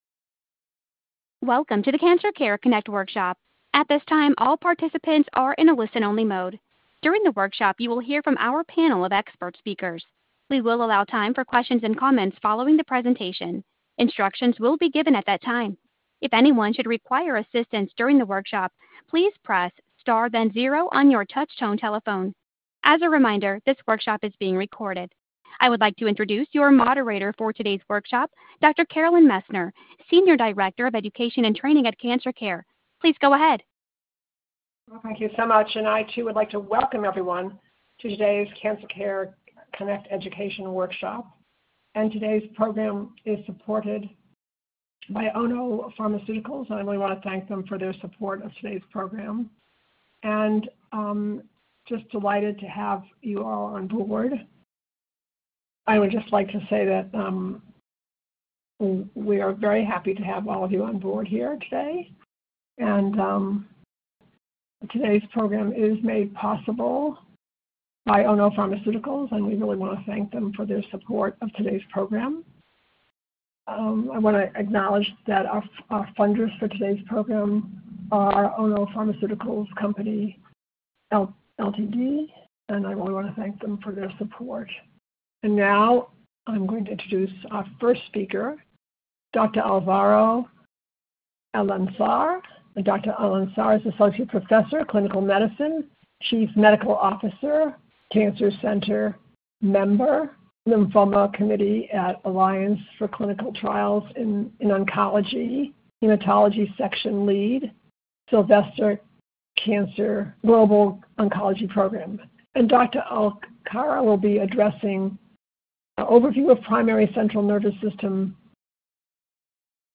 Questions for Our Panel of Experts